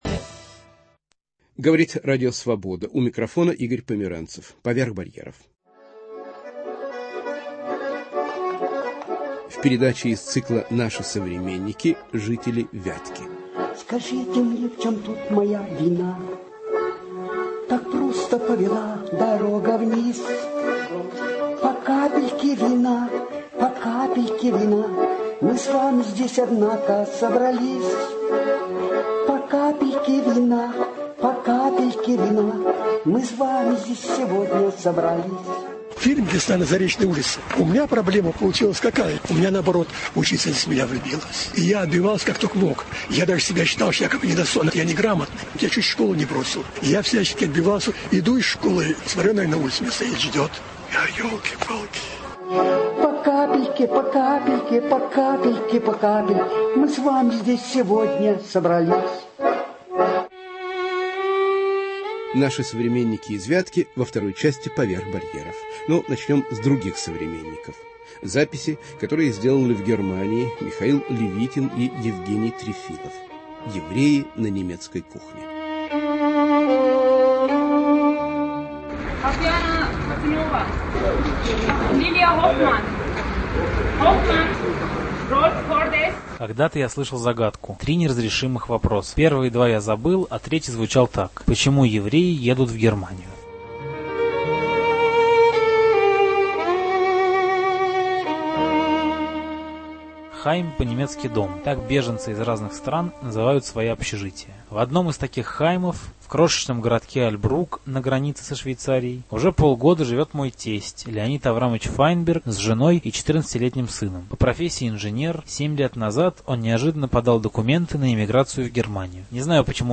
"Евреи на немецкой кухне" Разговоры с российскими и украинскими евреями, уехавшими в последние годы в Германию. Передача из цикла "Наши современники" Мать-одиночка и пенсионер из Вятки рассказывают о самых драматичных и комичных эпизодах своей жизни.